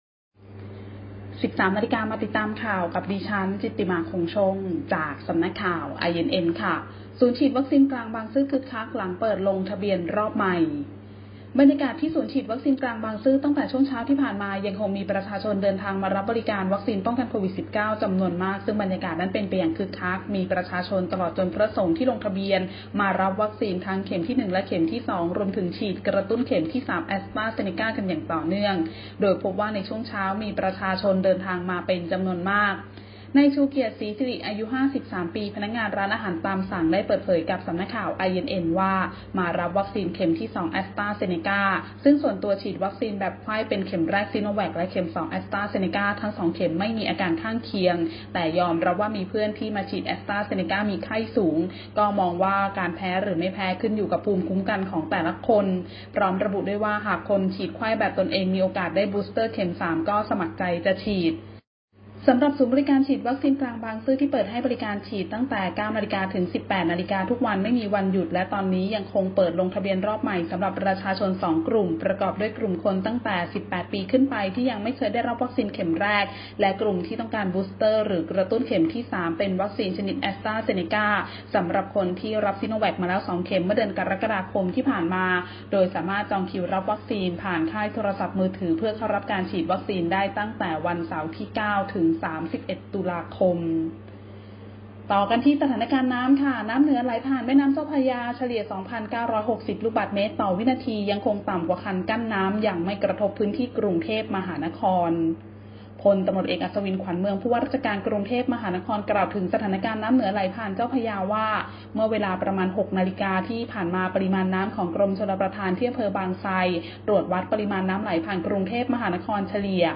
ข่าวต้นชั่วโมง 13.00 น.